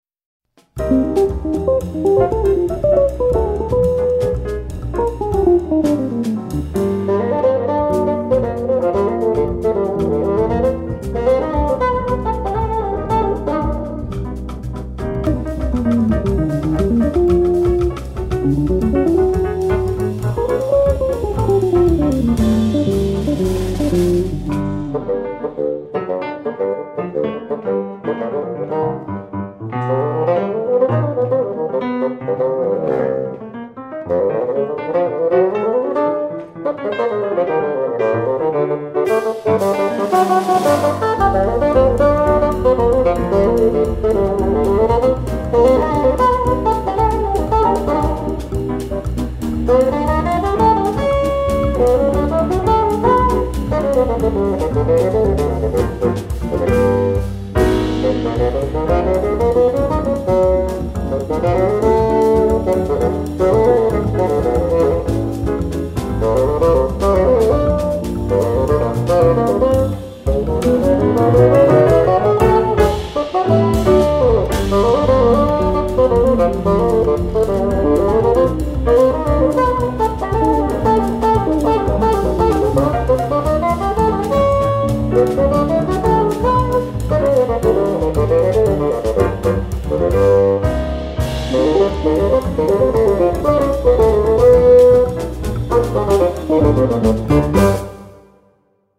o CD de um fagote tocando jazz
• o fagote nunca deixa de soar como fagote,
• a versatilidade do ritmo contagia,